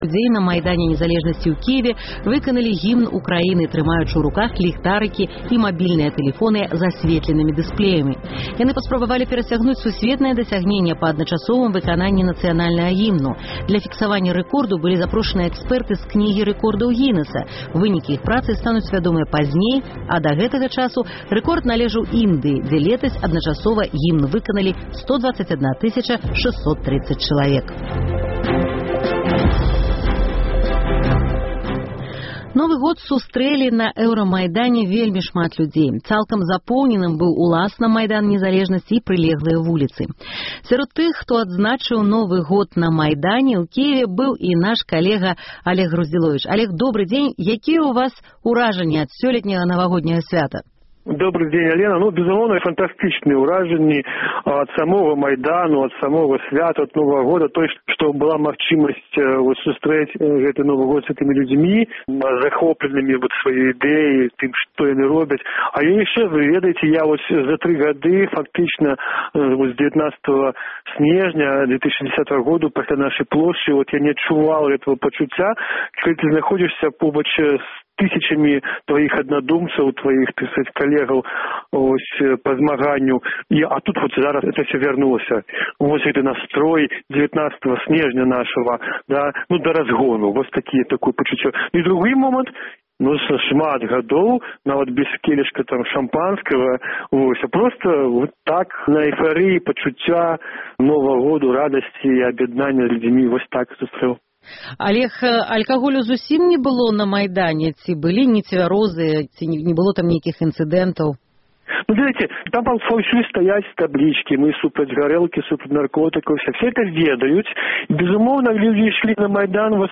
Вы пачуеце ягоныя ўражаньні ад навагодняга Майдану.